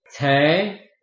臺灣客語拼音學習網-客語聽讀拼-饒平腔-單韻母
拼音查詢：【饒平腔】ce ~請點選不同聲調拼音聽聽看!(例字漢字部分屬參考性質)